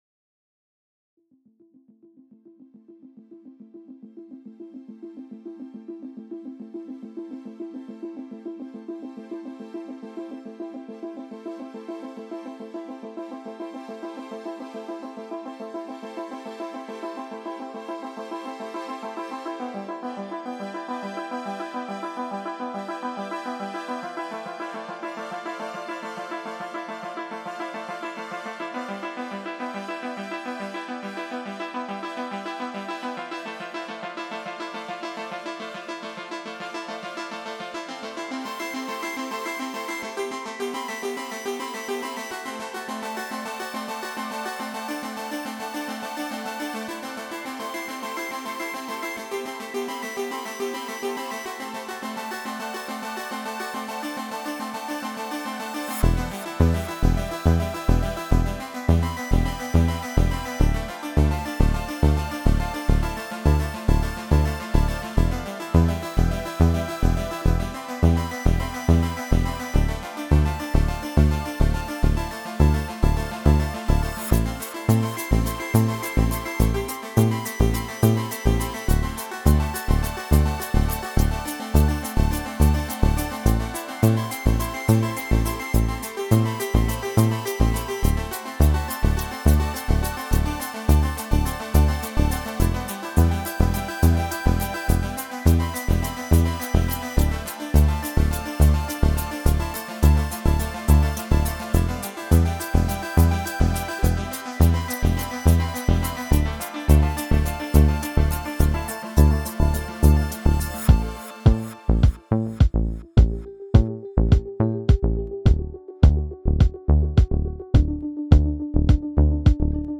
New tune ... space disco!